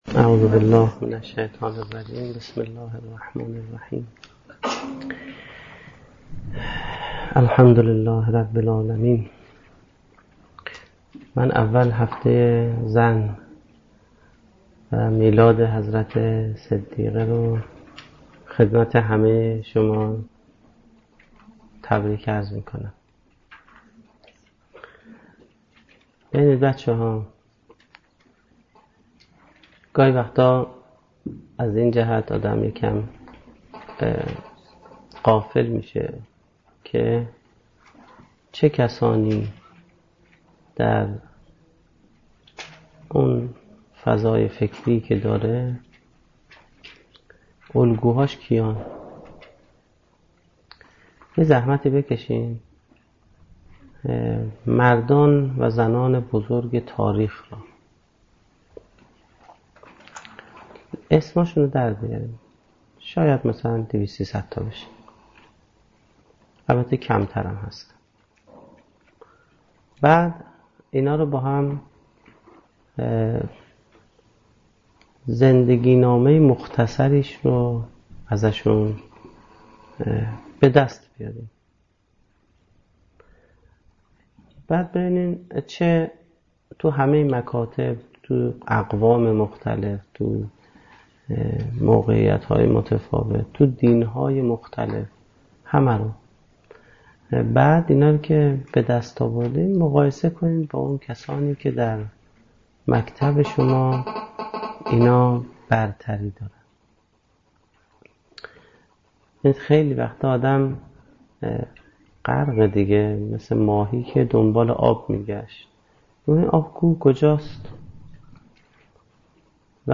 سخنرانی
در دانشگاه فردوسی